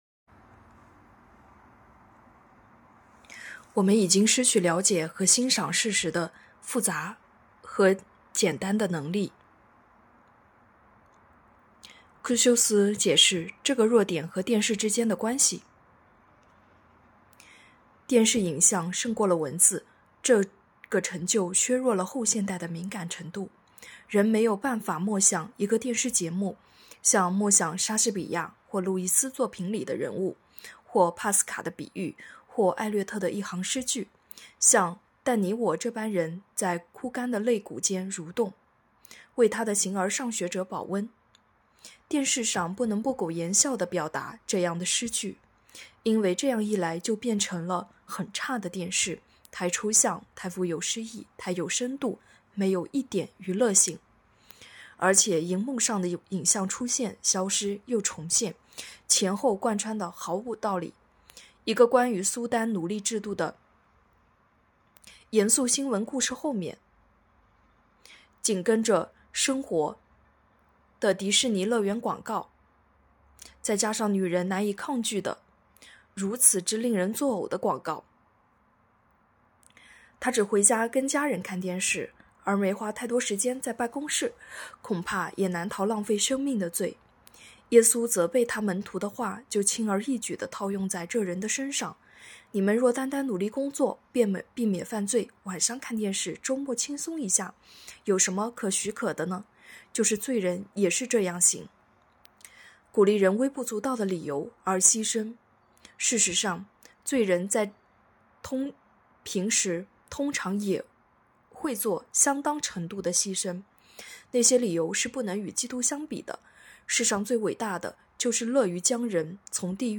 2024年6月6日 “伴你读书”，正在为您朗读：《活出热情》 欢迎点击下方音频聆听朗读内容 音频 https